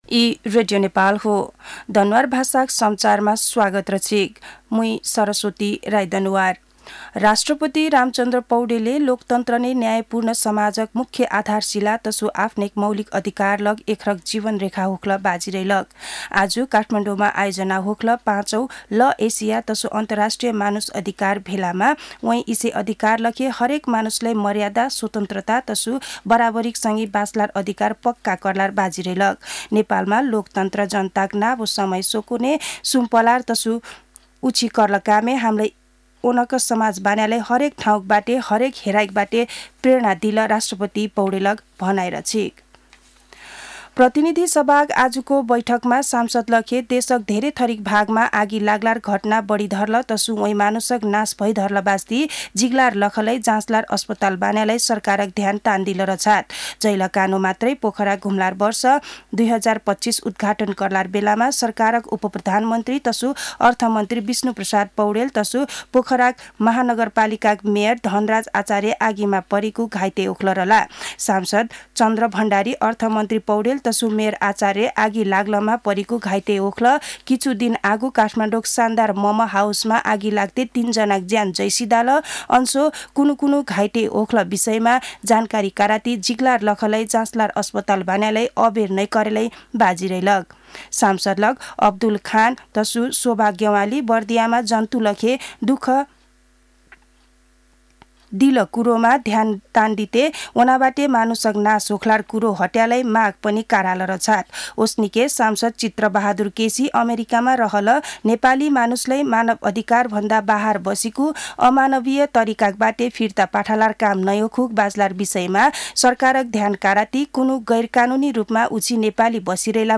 दनुवार भाषामा समाचार : ५ फागुन , २०८१